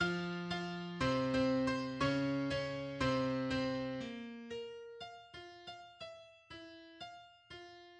This group alternates between loud and soft and combines two Bruckner hallmarks: octave falls and the Bruckner rhythm: